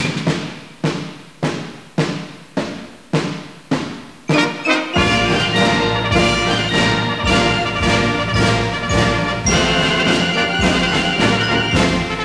Horns